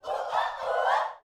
SHOUTS17.wav